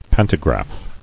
[pan·to·graph]